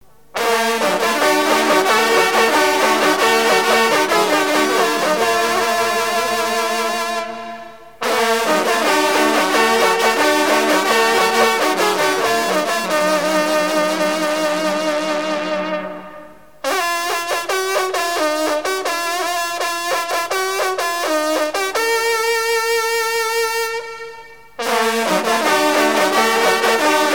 circonstance : rencontre de sonneurs de trompe
Pièce musicale éditée